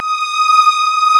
STRINGA.M1E5.wav